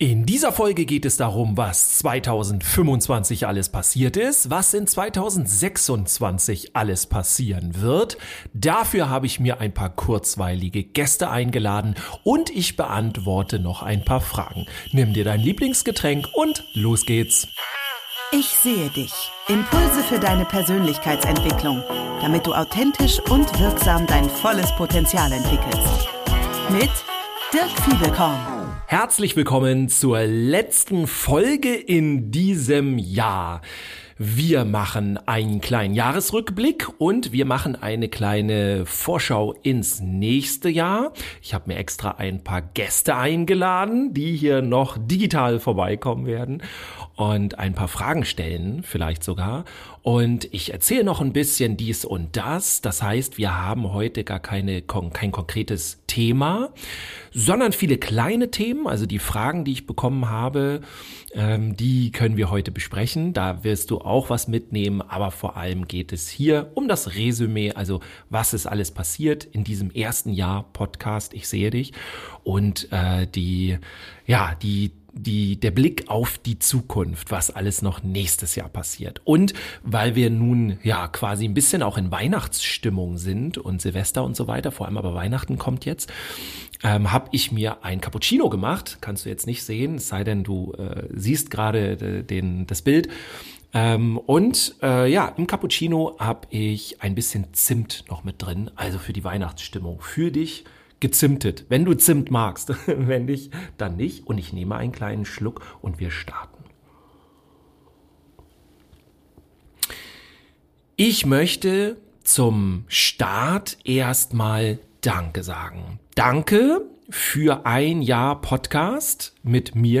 In dieser Folge schauen wir auf das vergangene Jahr zurück. Dafür kommen spontan ein paar Gäste vorbei und beantworte noch ein paar Fragen.